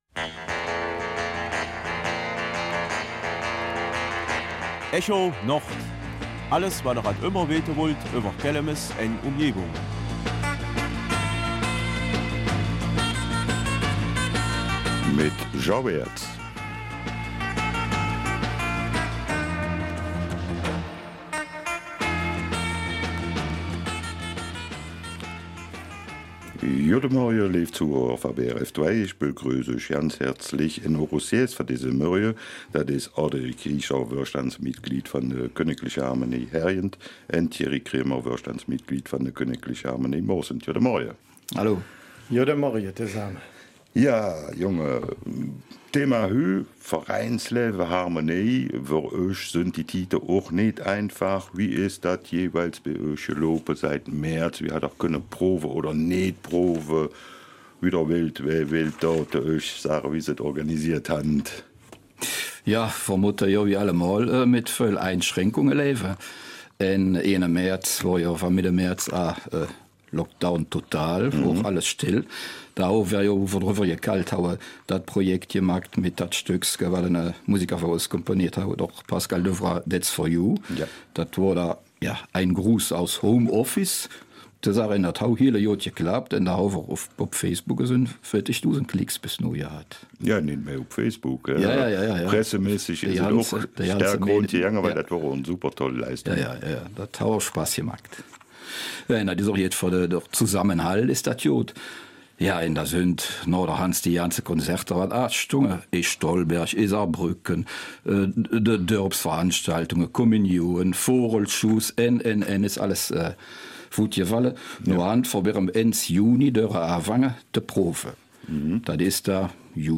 Kelmiser Mundart: Vereinsleben bei der Kgl. Harmonie Hergenrath und Moresnet